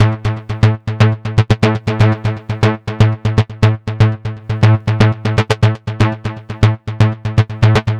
TSNRG2 Bassline 026.wav